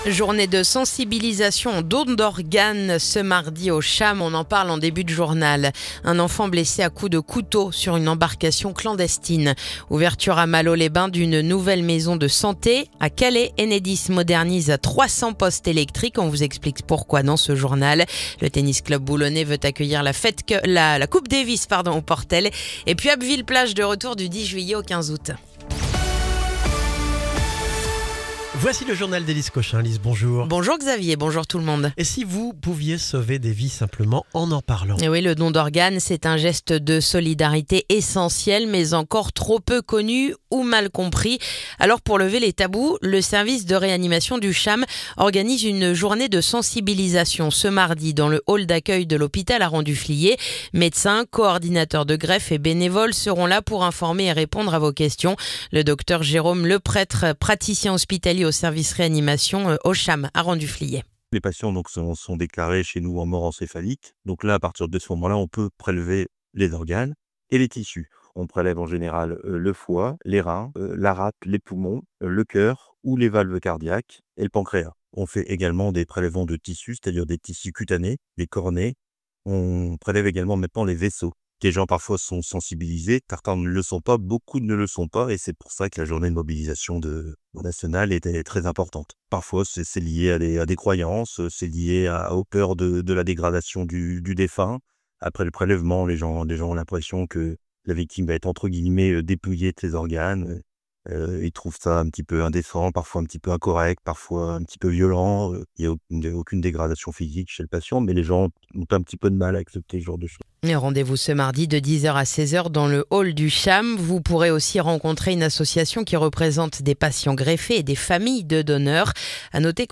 Le journal du mardi 17 juin